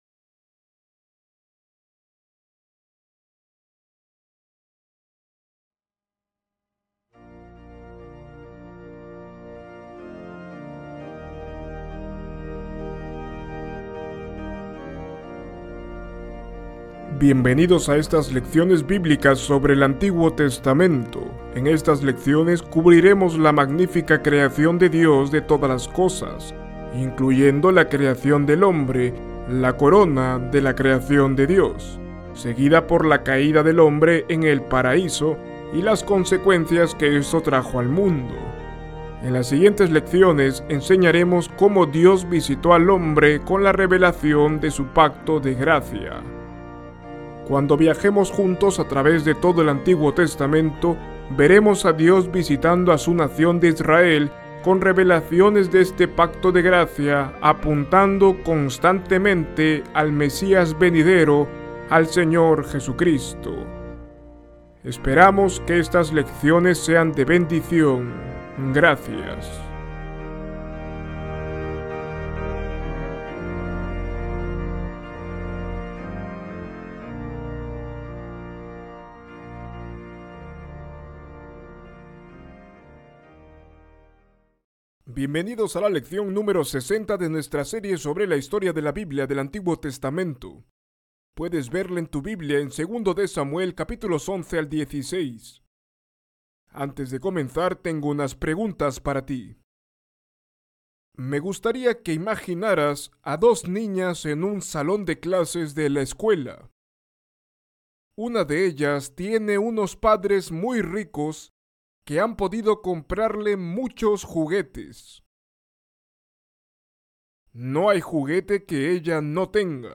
Esta lección muestra cómo incluso David cayó en un gravísimo pecado.